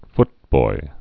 (ftboi)